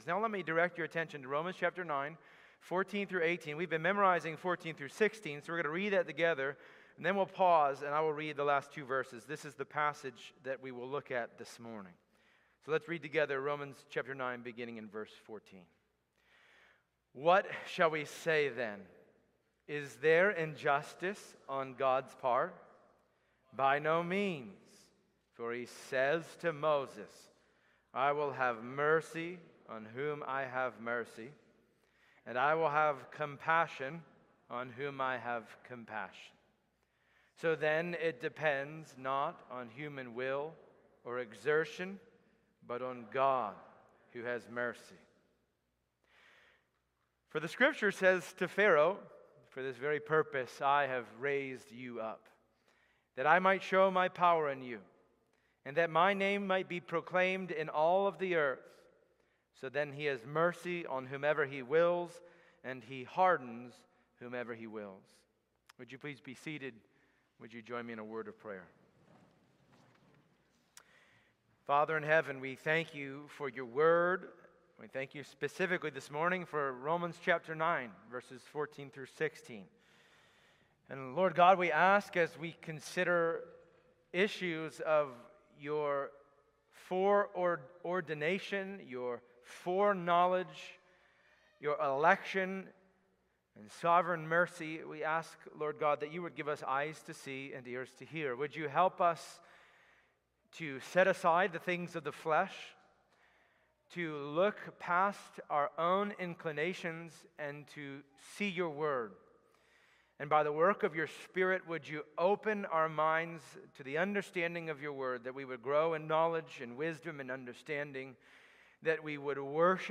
Passage: Romans 9:14-18 Service Type: Sunday Morning Download Files Bulletin « God’s Purpose of Election The Sovereignty of God